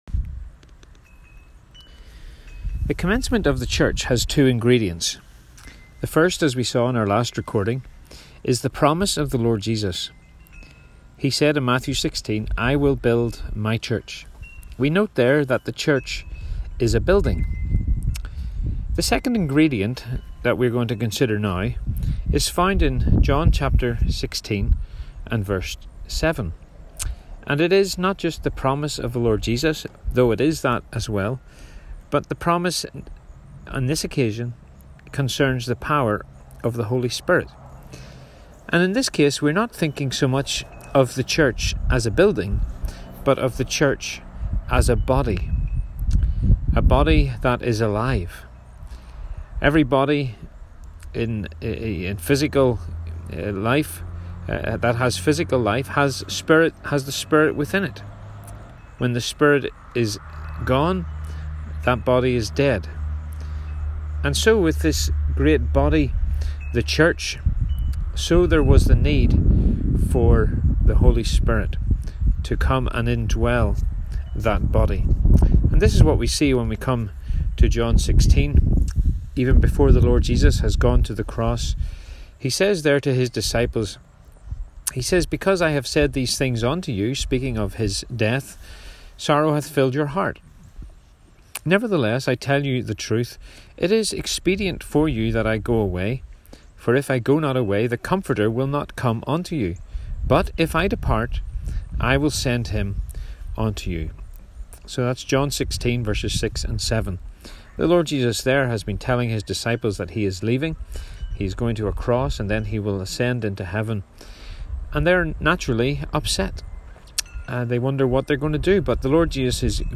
Bible Class Recordings